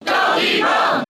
Donkey Kong's cheer in the US and PAL versions of Brawl.
Donkey_Kong_Cheer_English_SSBB.ogg